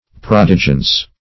Search Result for " prodigence" : The Collaborative International Dictionary of English v.0.48: Prodigence \Prod"i*gence\, n. [L. prodigentia, fr. prodigens, p. pr. of prodigere.